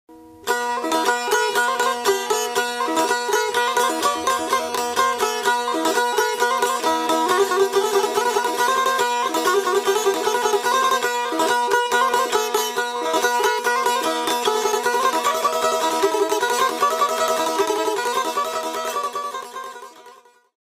Traditional Albanian Instrument
Cifteli
Audio file of the Cifteli
Cifteli-sound.mp3